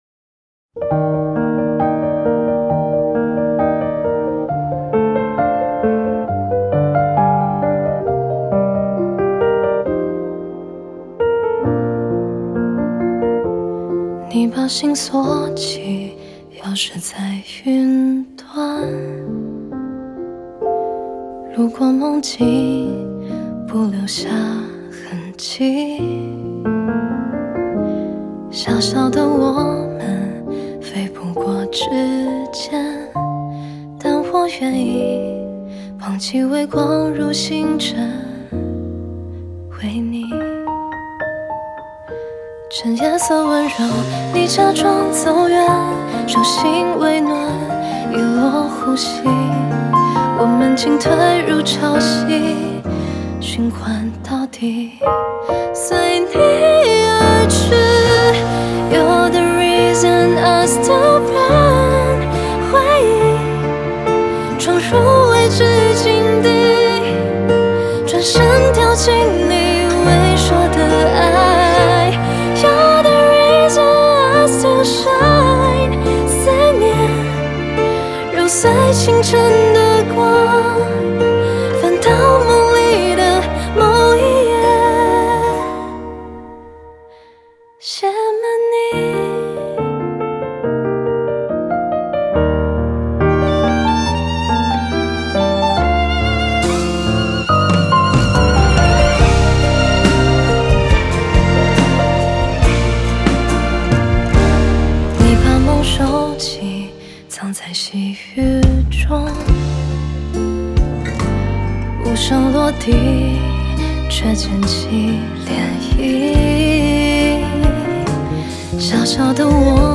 Ps：在线试听为压缩音质节选，体验无损音质请下载完整版
弦乐团
钢琴
吉他
贝斯
鼓
合声